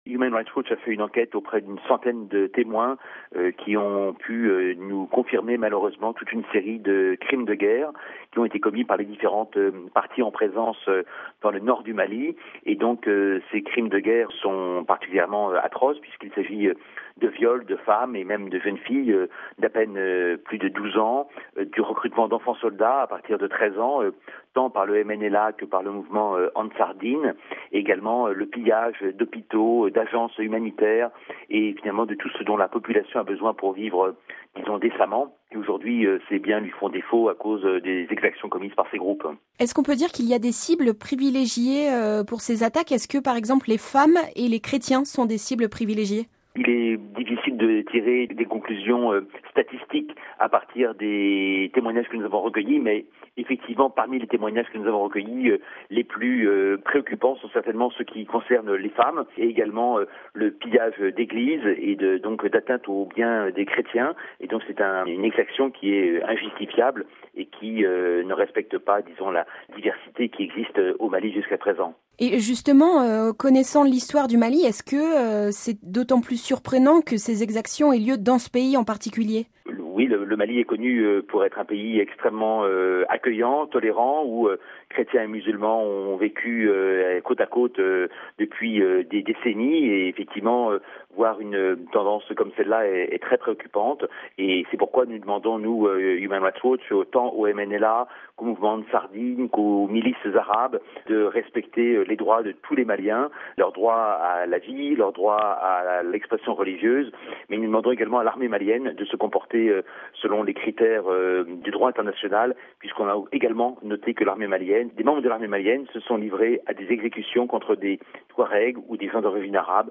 Propos recueillis